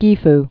(gēf)